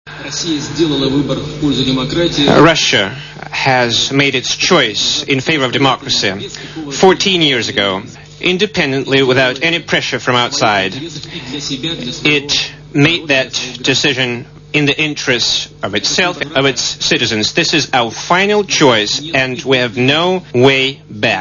Obaj przywódcy po spotkaniu na zamku w Bratysławie wspólnie uczestniczyli w konferencji prasowej.
Mówi prezydent Rosji Władimir Putin